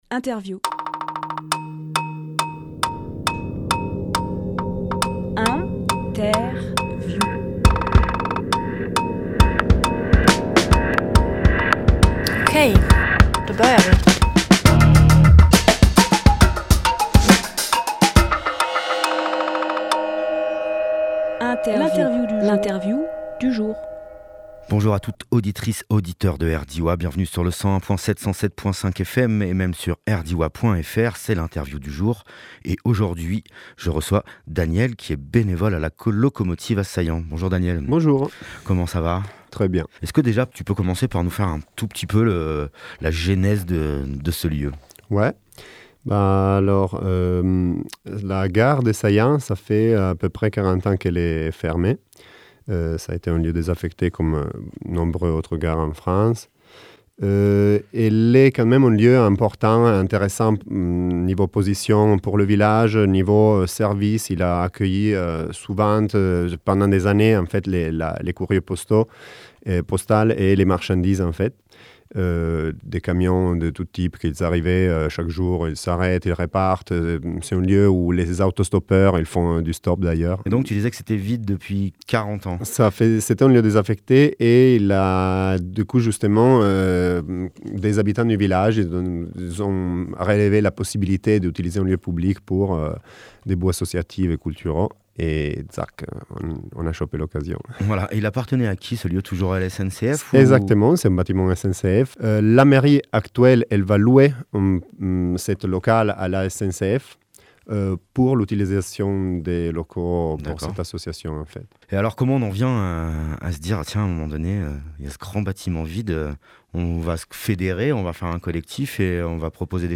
Emission - Interview La Locomotive de Saillans – Une gare insolite Publié le 31 octobre 2023 Partager sur…